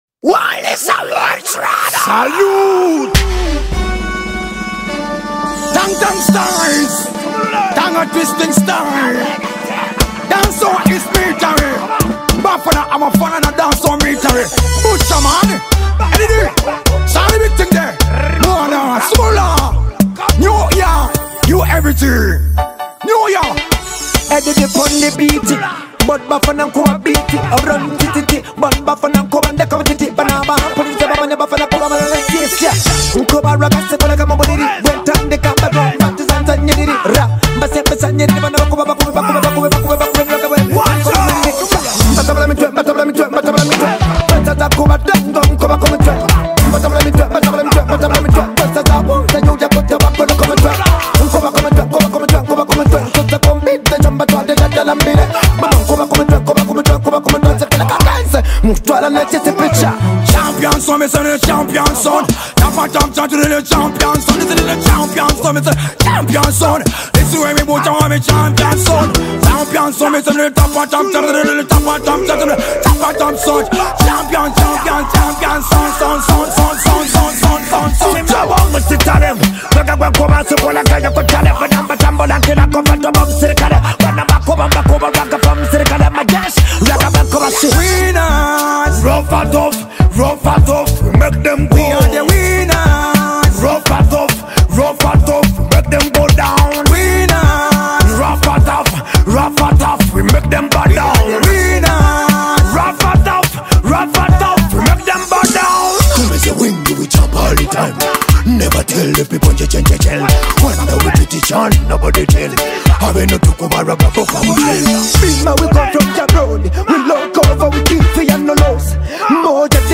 Genre: Dance Hall